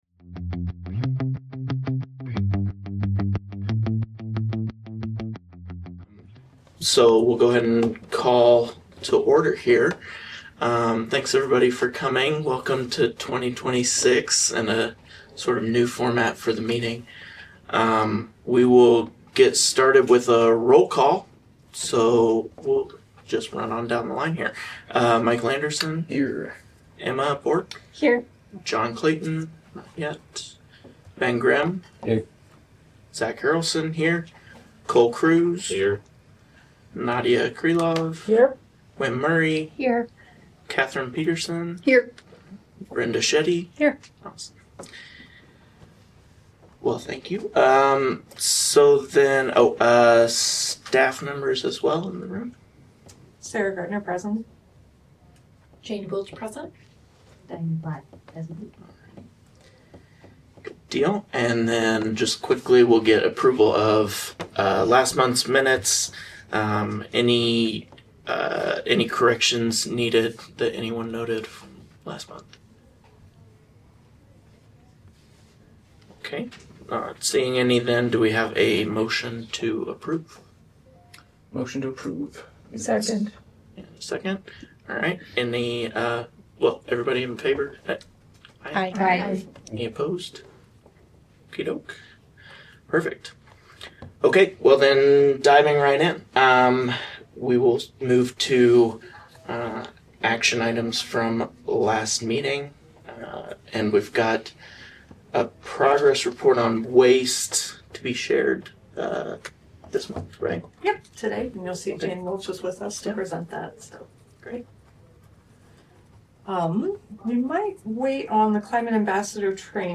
Regular monthly meeting of the Iowa City Climate Action Commission.